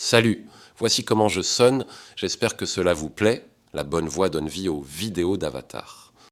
🌍 Multilingual👨 Мужской
Пол: male